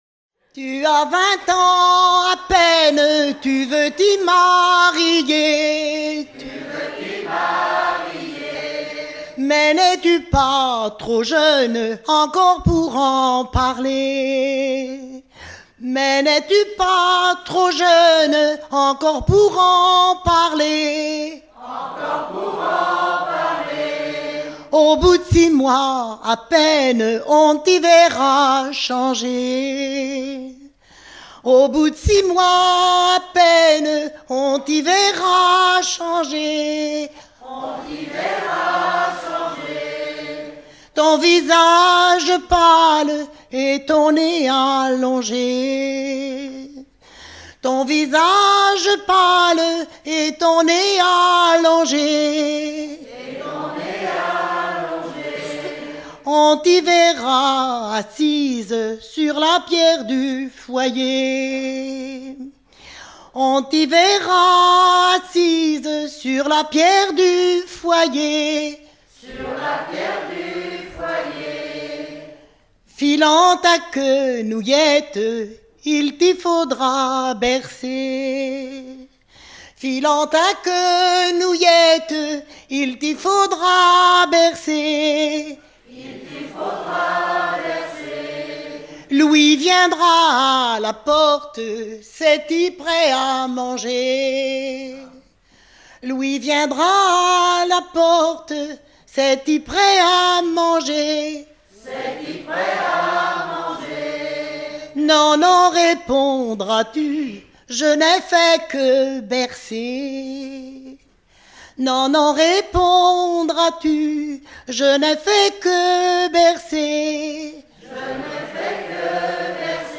Genre dialogue
Catégorie Pièce musicale éditée